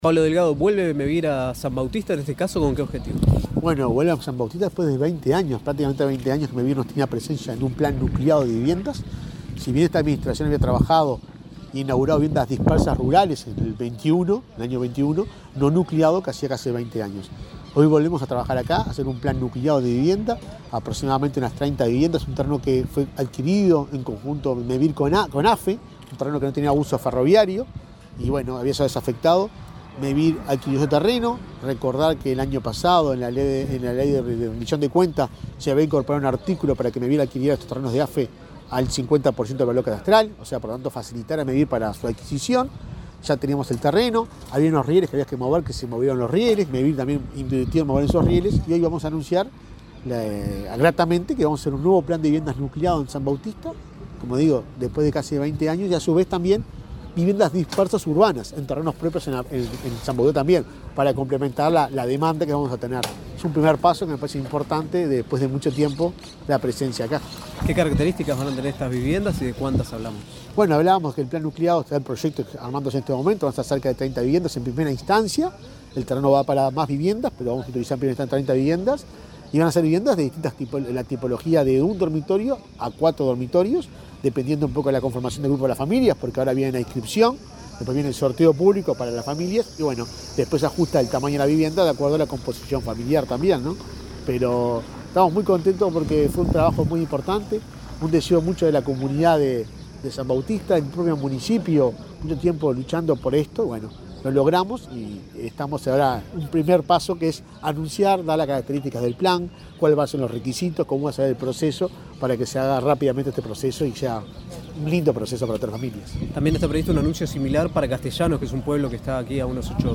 Declaraciones del presidente de Mevir, Juan Pablo Delgado
Declaraciones del presidente de Mevir, Juan Pablo Delgado 10/08/2023 Compartir Facebook X Copiar enlace WhatsApp LinkedIn El presidente de Mevir, Juan Pablo Delgado, dialogó con la prensa en Canelones, donde anunció un nuevo plan de viviendas para la localidad de San Bautista.